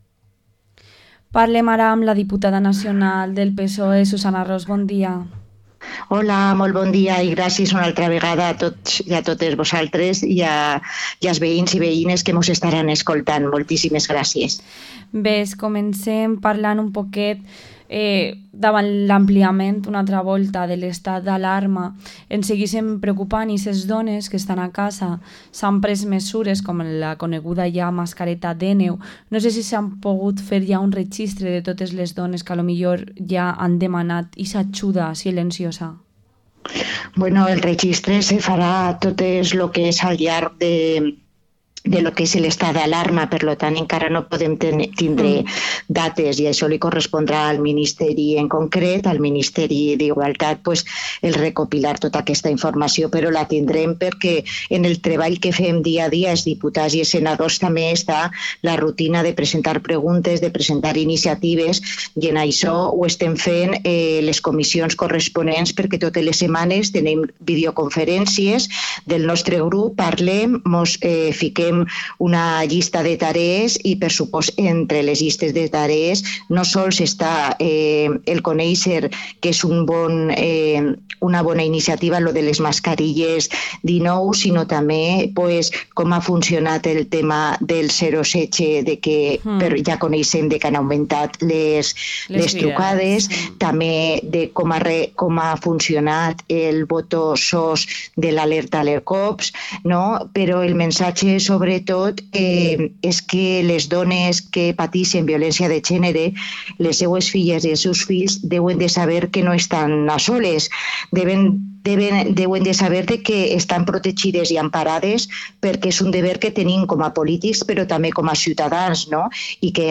Entrevista a la diputada nacional del PSPV-PSOE y miembro de la Ejecutiva Federal, Susana Ros